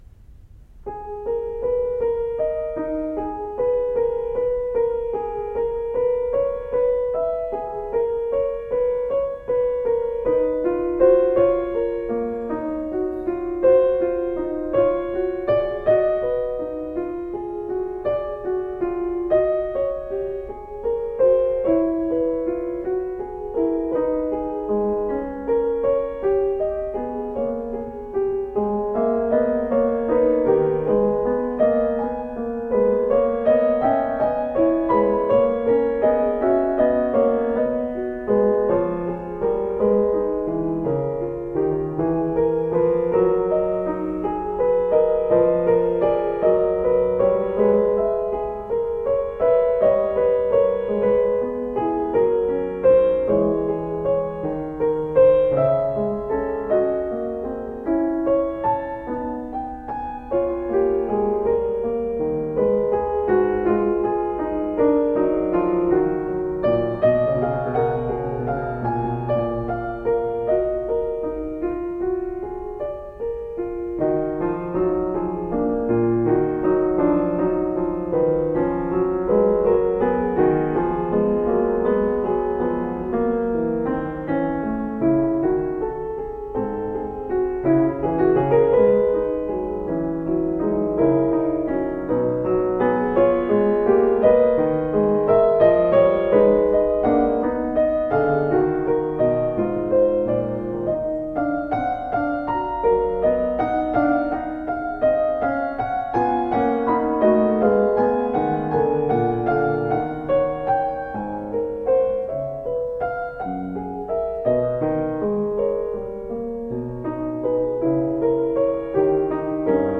solo piano music
Classical, Baroque, Instrumental Classical, Classical Piano